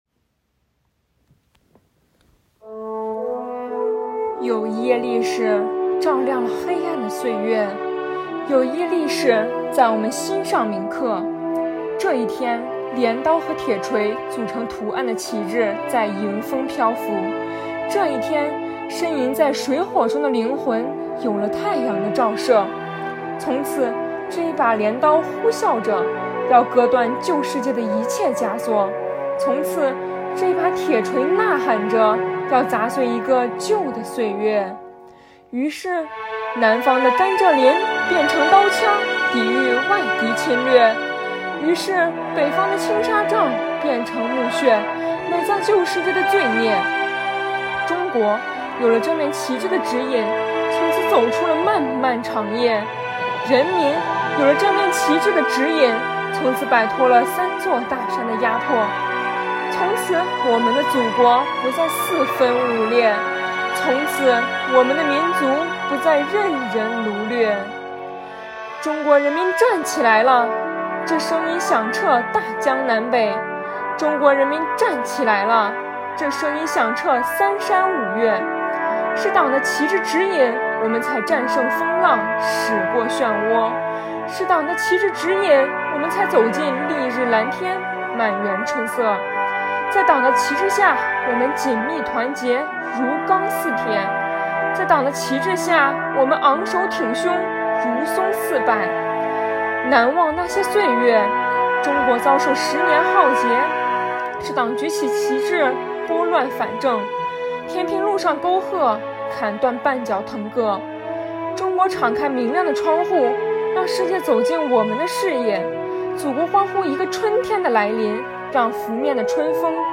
以朗诵为载体，用诗篇来明志
朗诵